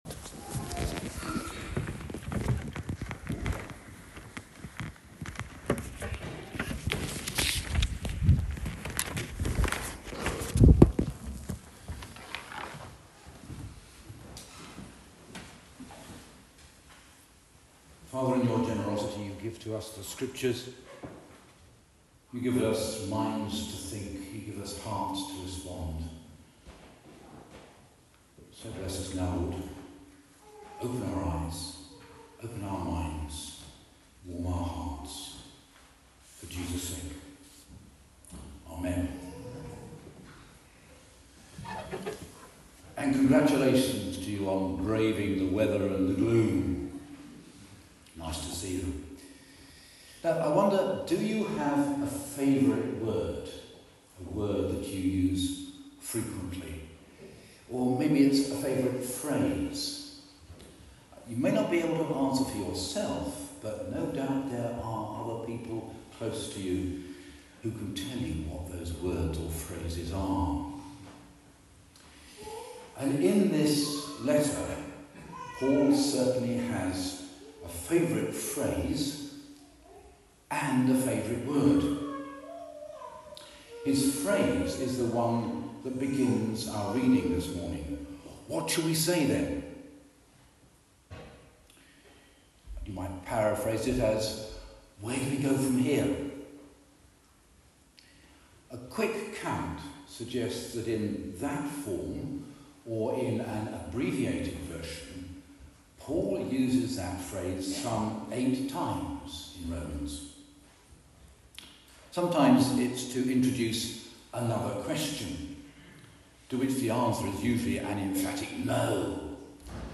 Romans 9:30-10:4 Service Type: Sunday Service « Romans 9:14-29